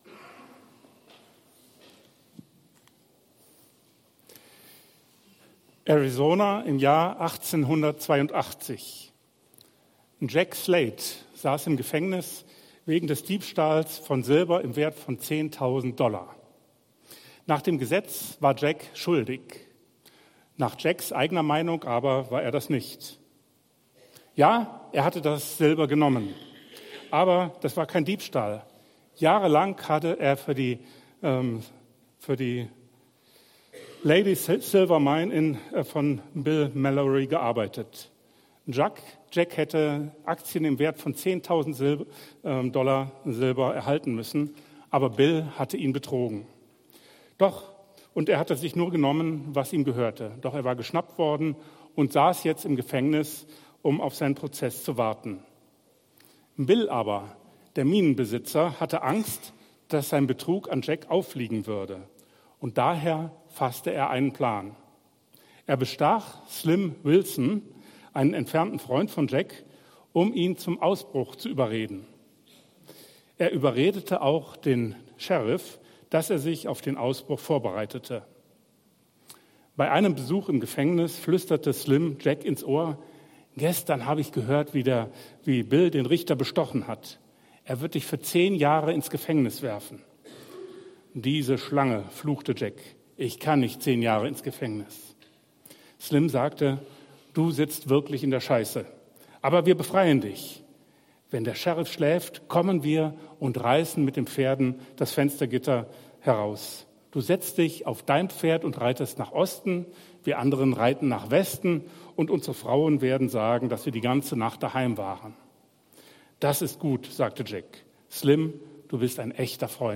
Re:veal(enthüllen) – Das Problem ist nicht die Moral ~ EFG-Haiger Predigt-Podcast Podcast